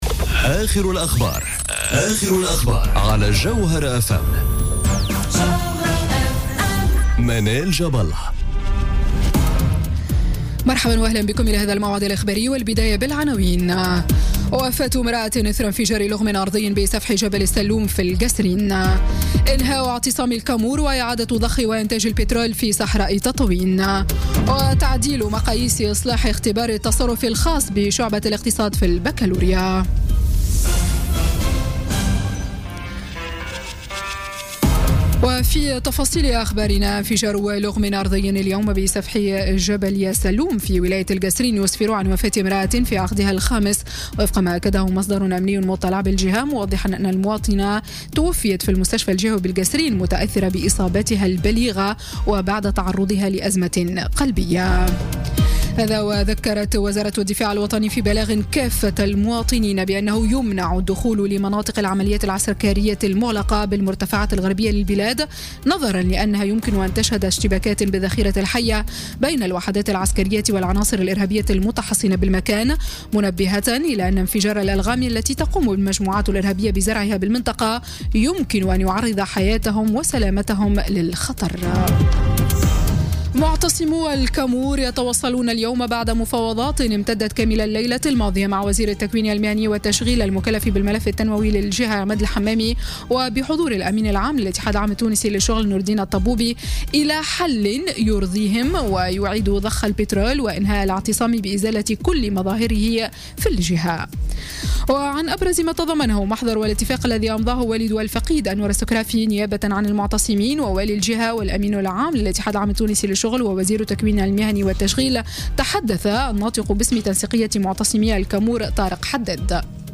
نشرة أخبار السادسة مساء ليوم الجمعة 16 جوان 2017